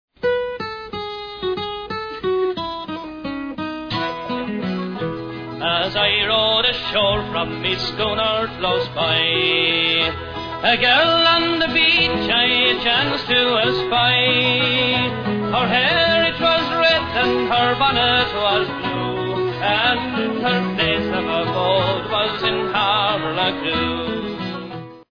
guitar
bass